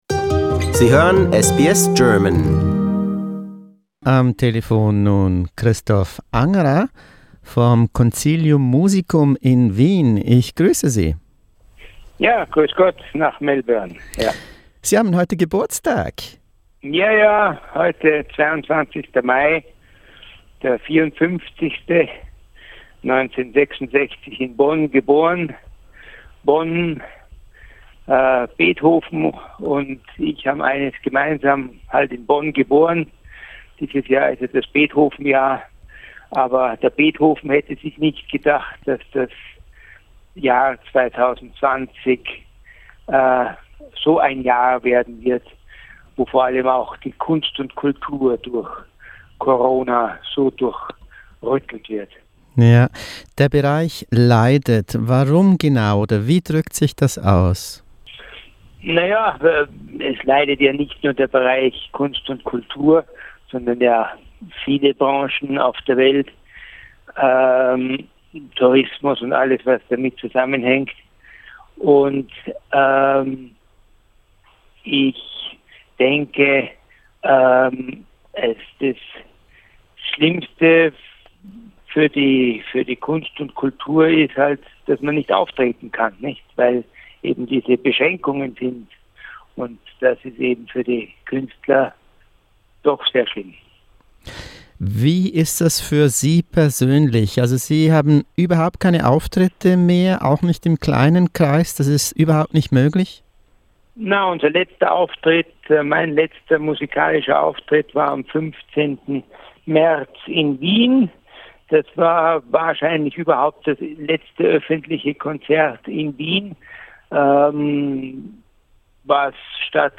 Vom Staat gab es ganze 1,000 Euro - davon kann kein Künstler leben. Ein Geburtstagsinterview.